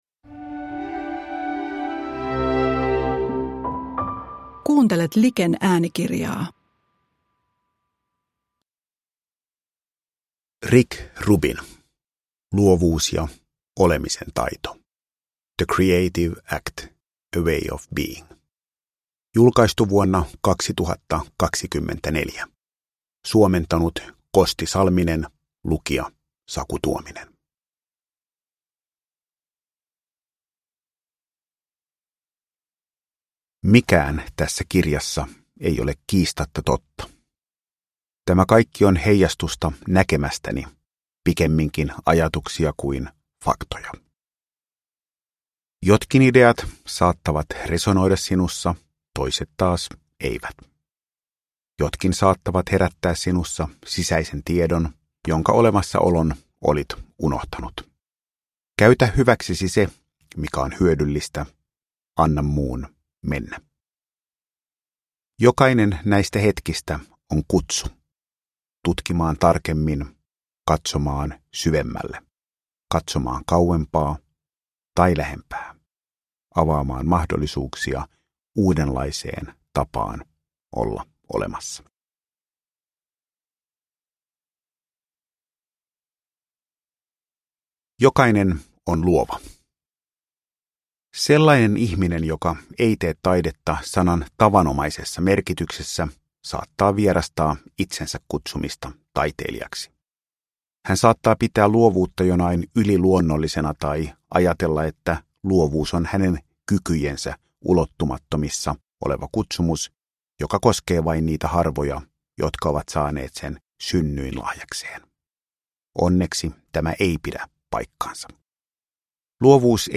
Luovuus ja olemisen taito – Ljudbok